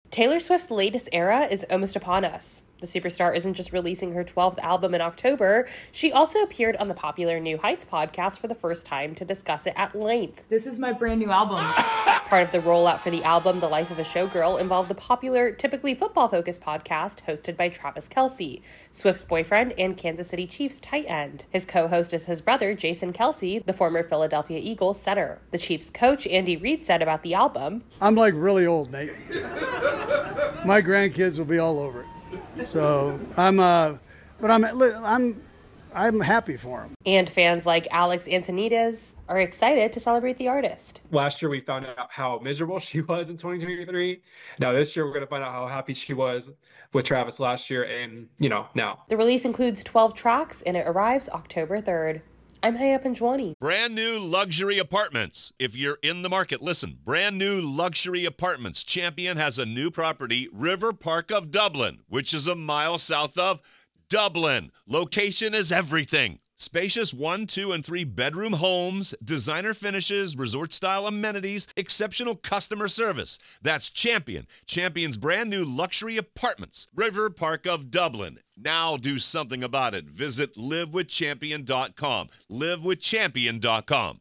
reports on reactions to Taylor Swift's new album, 'The Life of a Showgirl.'